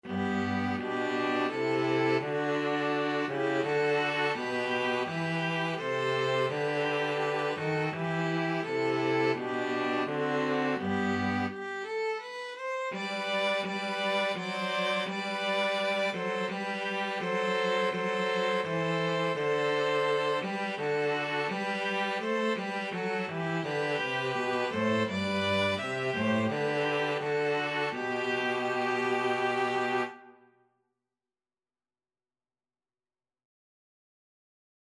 ViolinViolaCello
G major (Sounding Pitch) (View more G major Music for String trio )
3/4 (View more 3/4 Music)
String trio  (View more Easy String trio Music)